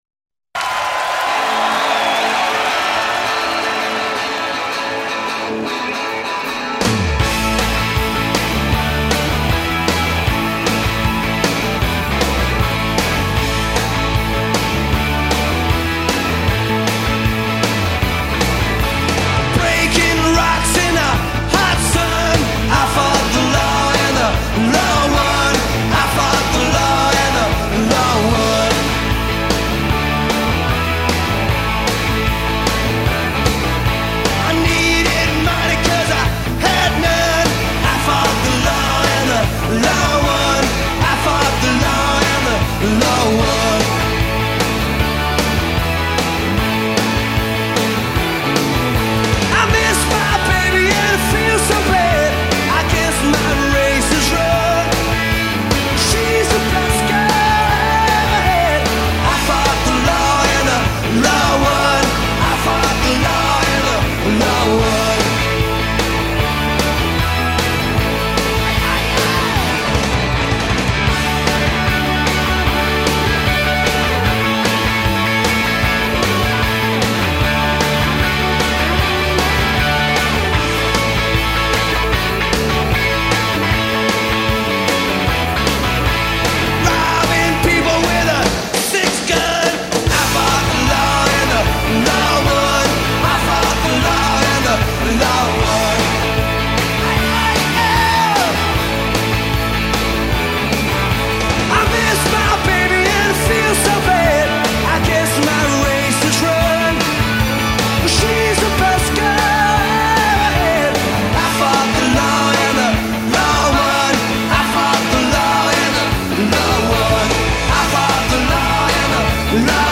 sizzling performance